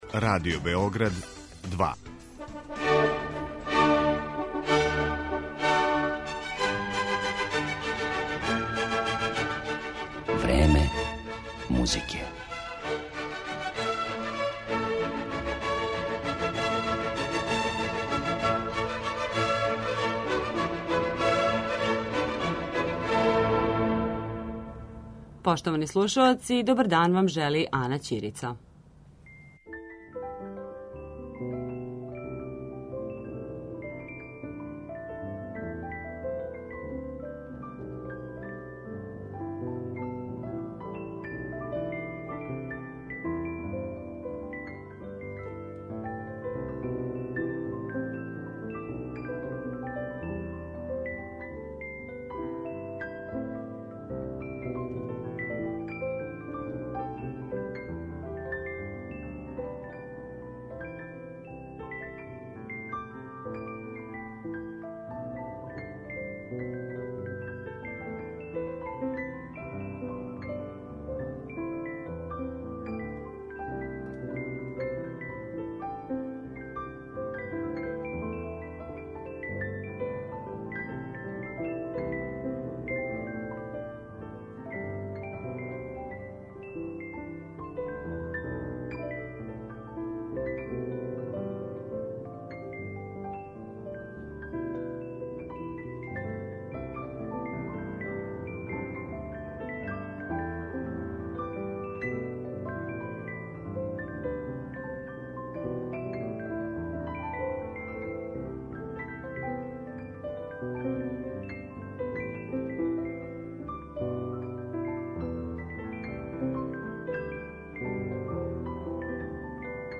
гости емисије су композитори